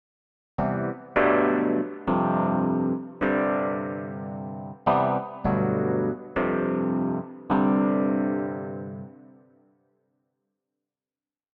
A pedal tone (or pedal note, or pedal point) is a sustained single note, over which other potentially dissonant parts are played.
Alongside the original note, these mappers are routed to FLEX with a Rhodes sample set loaded. It sounds like this (I'm playing just one or two keys at a time here):
Patcher_pedal_tone.mp3